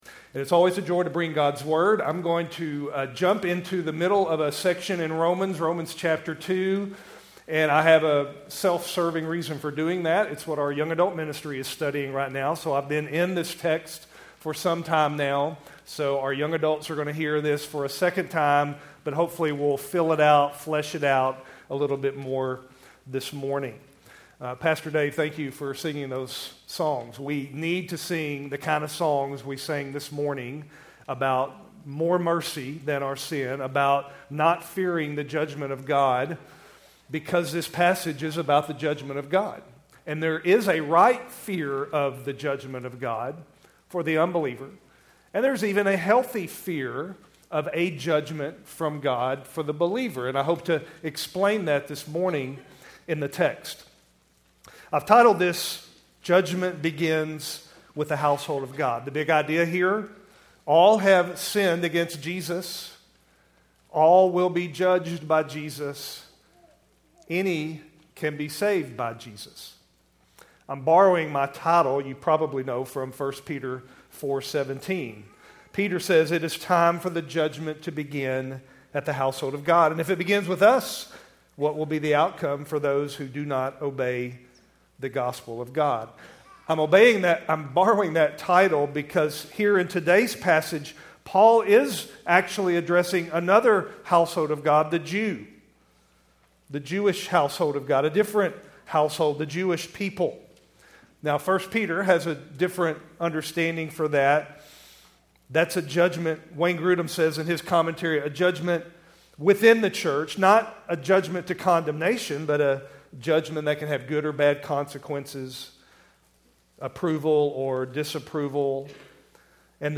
Keltys Worship Service, May 25, 2025